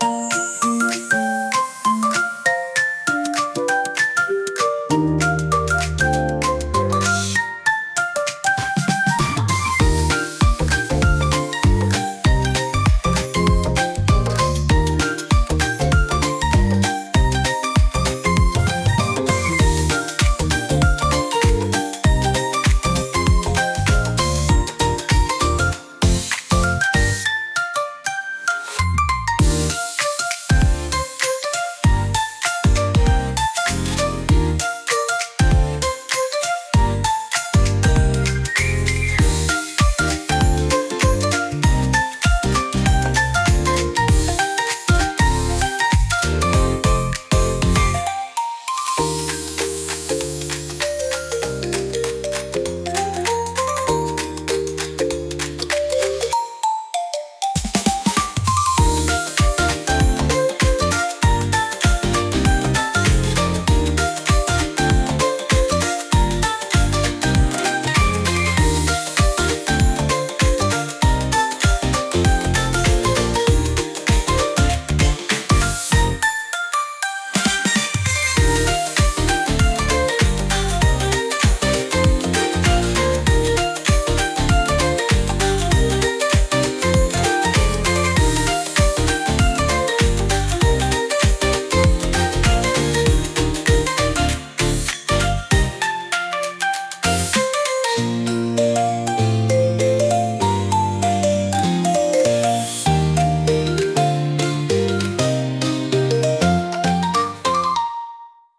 何かを準備するときにワクワクするような音楽です。